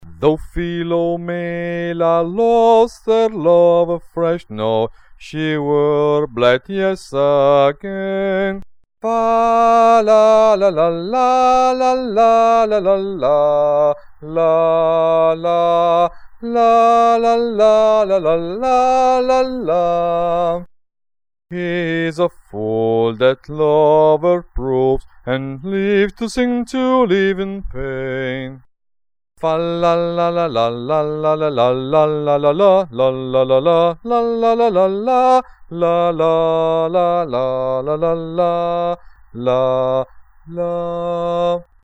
UOMINI